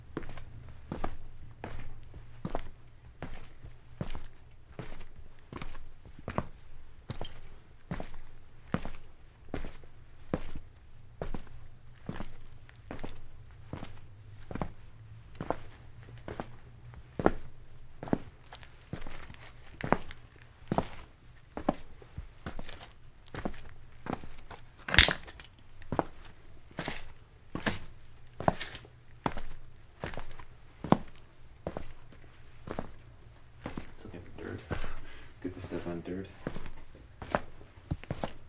随机 " 脚步声 鞋子 肮脏的混凝土未完成的地下室地板
描述：脚步鞋脏混凝土未完成的地下室floor.flac
Tag: 未完成 脚步声 地下室 水泥 地板